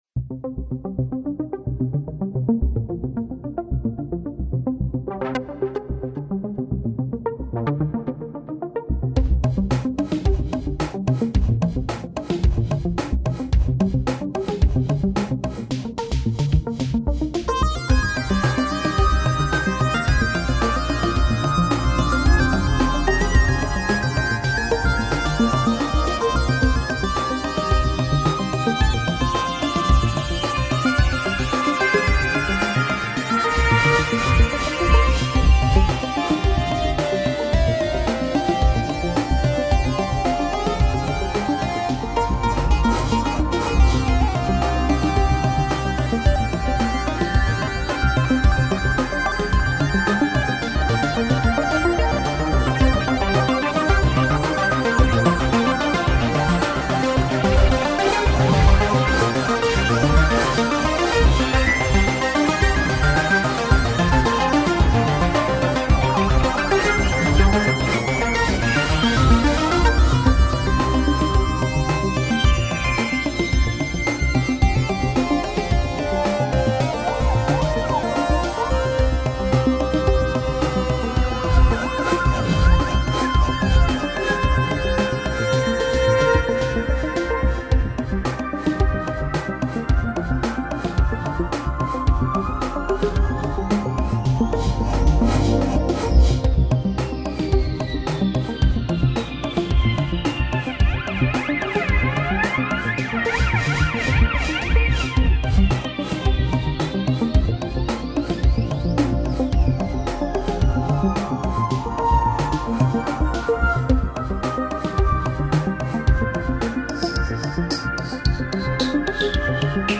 Είναι η πρώτη φορά που γράφω με 4 synthesizers. Όλα KORG :-) Το Wavestation, X3, O3R/W, Polysix, MDE-X (effects).